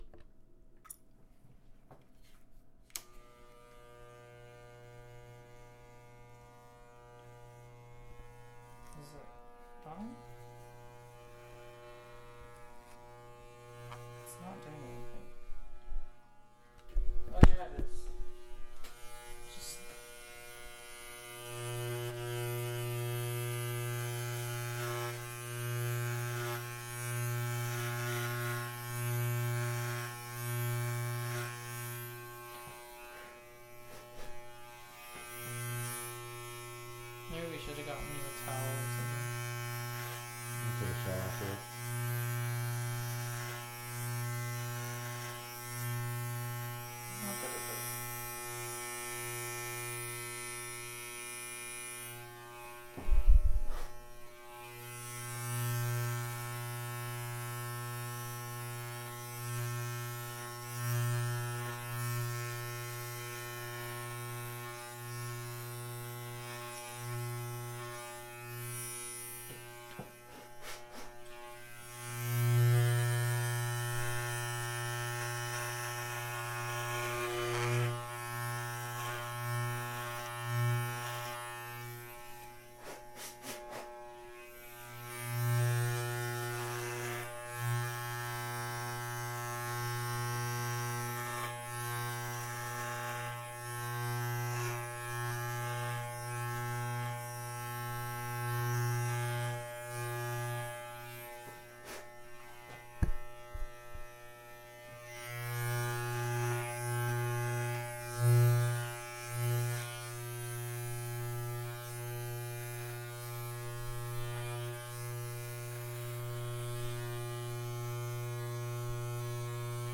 Buzzcut
using a pair of hair clippers
with a Zoom H4n Handy Recorder